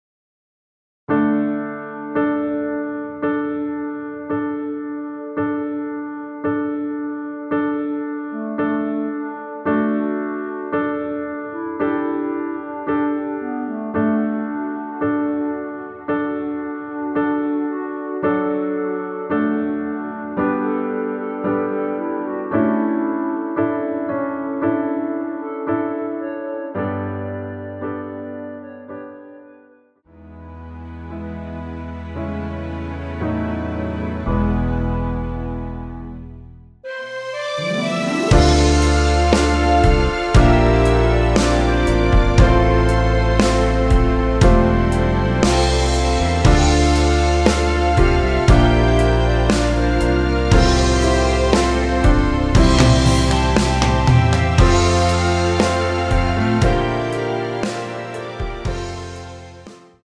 멜로디 MR이라고 합니다.
앞부분30초, 뒷부분30초씩 편집해서 올려 드리고 있습니다.
중간에 음이 끈어지고 다시 나오는 이유는